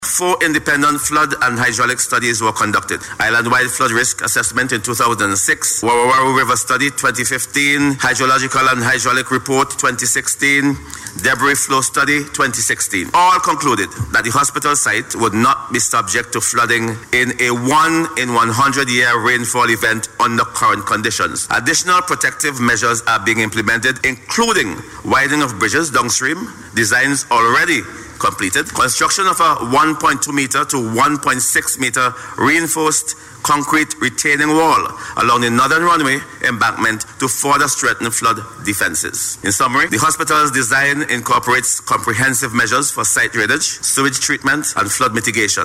Responding to a question in Parliament, Minister Prince explained that while the area is assumed to have a high water table and poorly drained soils, four independent flood and hydraulic studies have been conducted.